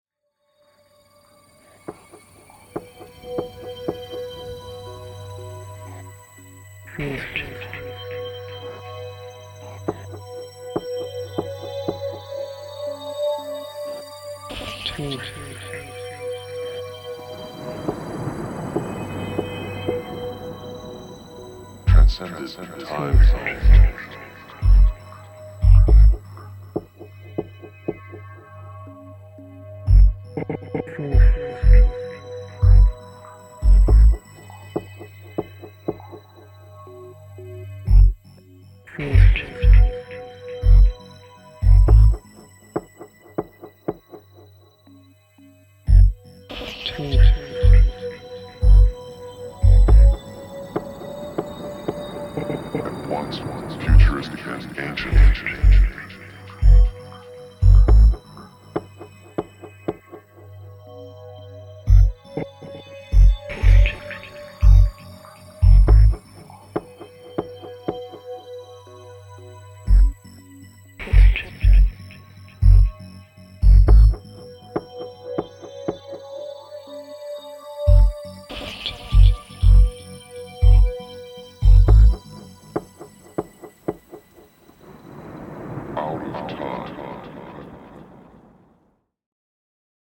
House Techno Trance